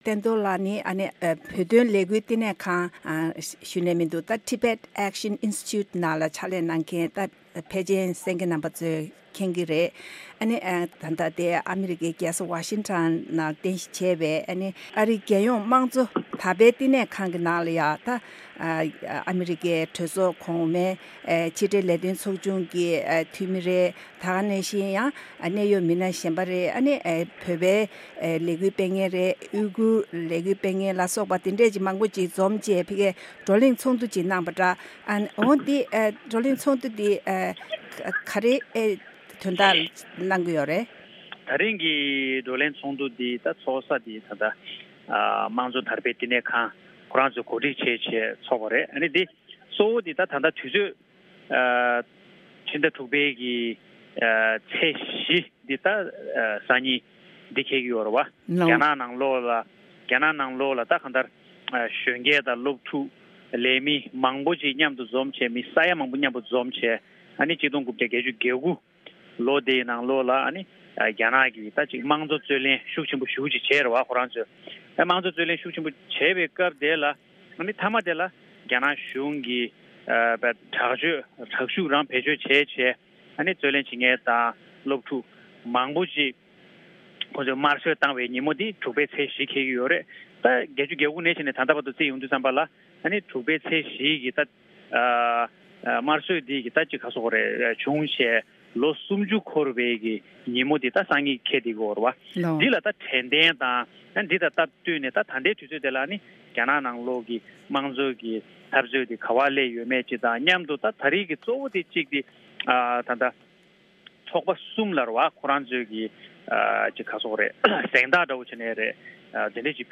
༡༩༨༩་ལོར་པེ་ཅིང་གནམ་བདེ་སྒོ་མོ་ཆེའི་ཐང་དུ་རྒྱ་ནག་གི་སློབ་ཕྲུག་གཙོ་བོར་གྱུར་བའི་མི་མང་སྟོང་ཕྲག་མང་པོས་རྒྱ་ནག་གཞུང་ལ་ངོ་རྒོལ་བྱས་ནས་དམངས་གཙོའི་ལས་འགུལ་སྤེལ་ཏེ། རྒྱ་ནག་གཞུང་གིས་སློབ་ཕྲུག་ཚོར་བསམ་ཡུལ་ལས་འདས་པའི་དྲག་གནོན་ཚབས་ཆེན་བྱས་ནས་ལོ་༣༠་ཐམ་པ་འཁོར་བའི་དུས་དྲན་ལ་ཨ་རིའི་རྒྱལ་ཡོངས་དམངས་གཙོ་ཐེབས་རྩ་ཁང་དུ་ཨ་རིའི་གནད་ཡོད་མི་སྣ་ཁག་གིས་གཏམ་བཤད་བྱས་པ་དེའི་སྐབས་སུ་བོད་པ་དང་ཞིན་ཅང་གི་ཡུ་གུར་བ། ད་དུང་རྒྱ་ནག་གི་ཡེ་ཤུའི་ཆོས་དད་པ་བཅས་བགྲོ་གླེང་ཚོགས་འདུ་